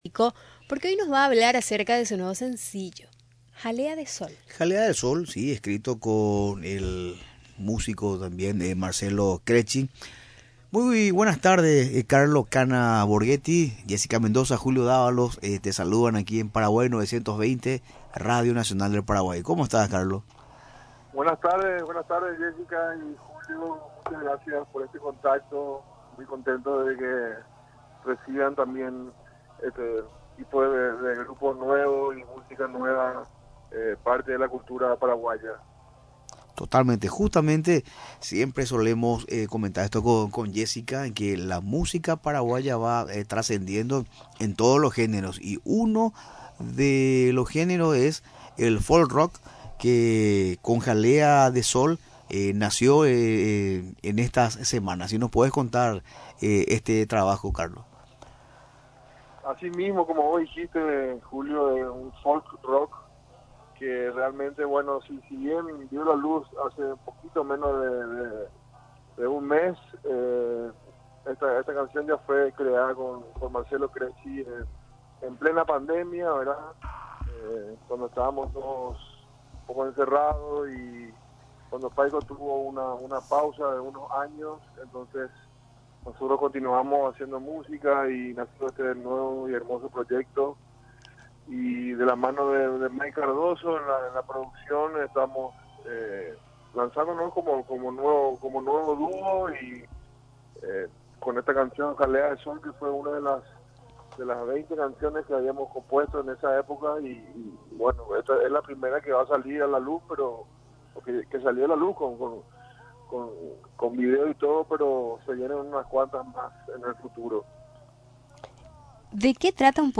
En entrevista en el programa Paraguay 920